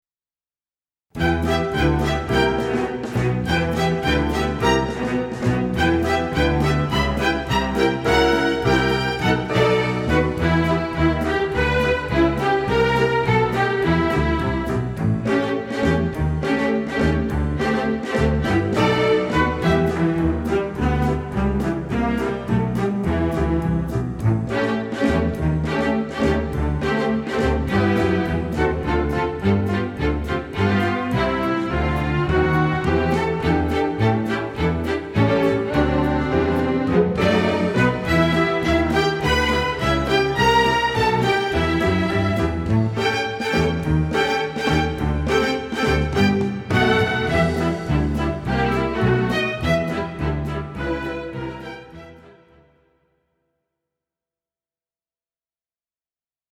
Gattung: für Streichorchester
Besetzung: Streichorchester